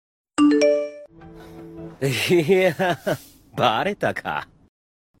Nada notifikasi WA Mitsuya
Kategori: Nada dering